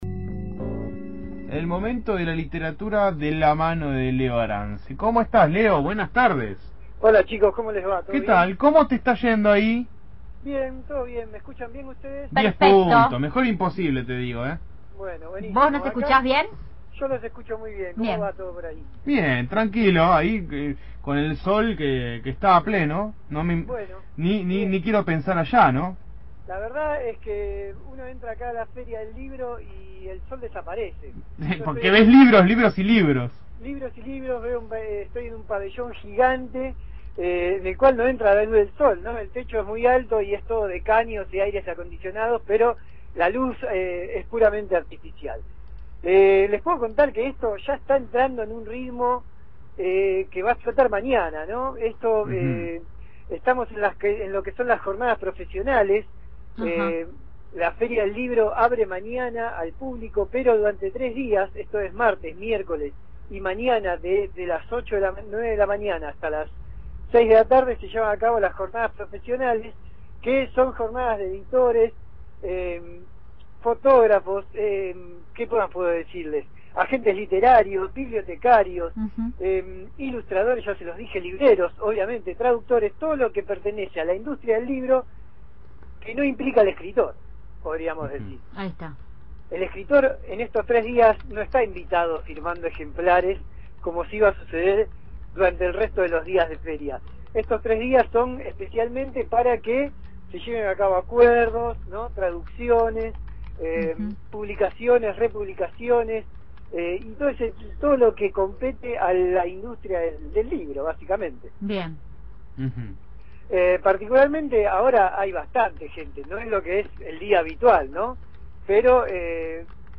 Dame una señal», esta vez desde el predio de La Rual, en Buenos Aires